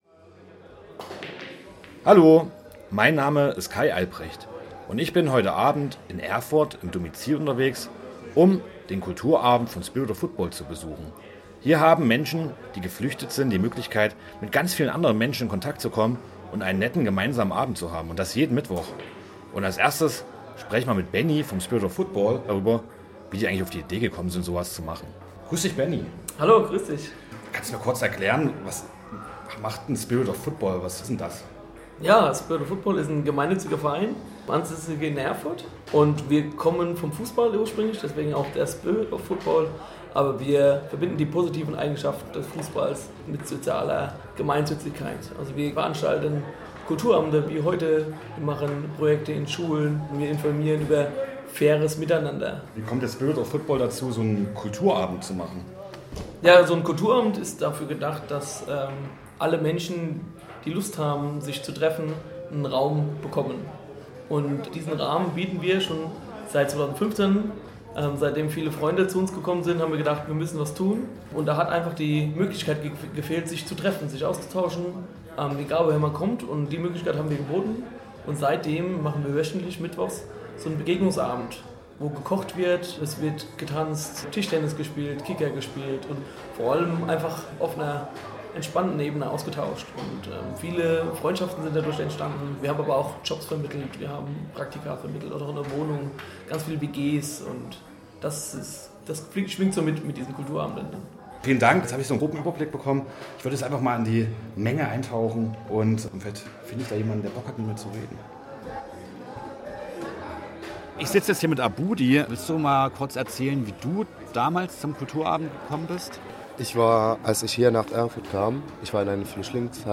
Hörreportage – Ein Besuch beim Spirit of Kulturabend